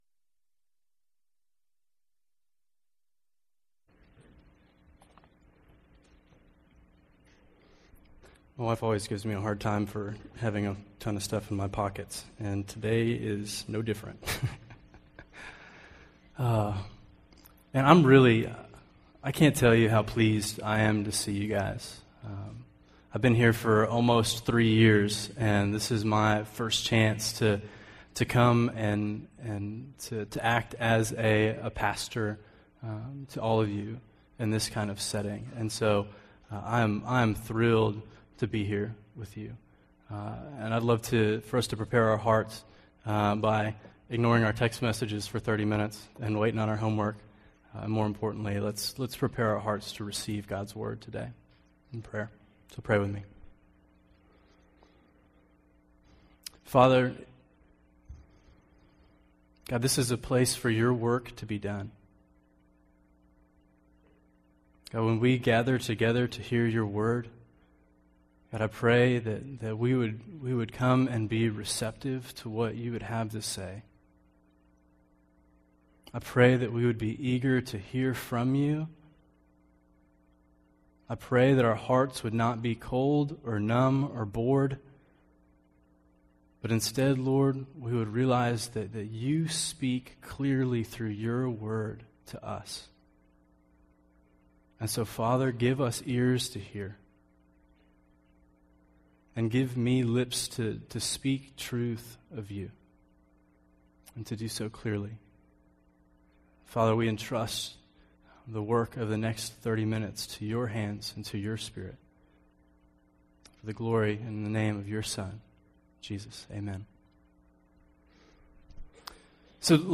Chapels
Address: “Hold On, Don’t Let Go” from 2 John 4-6